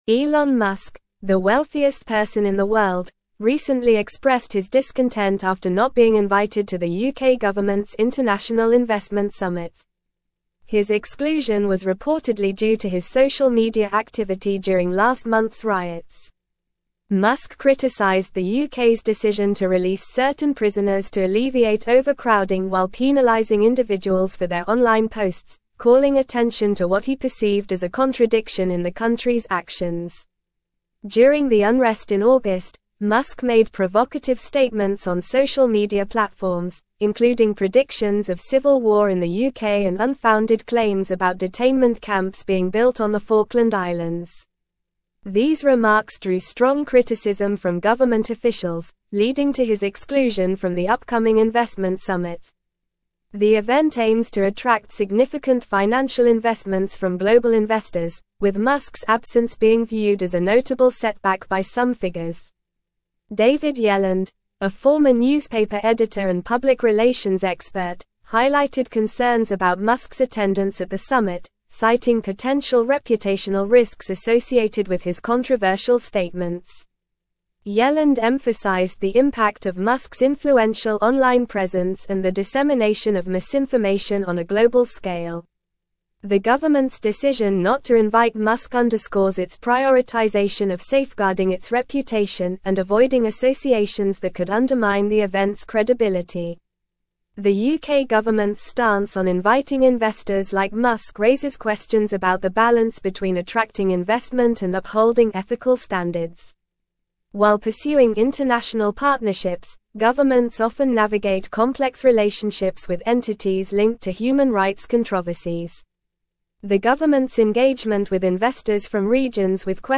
Normal
English-Newsroom-Advanced-NORMAL-Reading-Elon-Musk-Excluded-from-UK-Investment-Summit-Over-Posts.mp3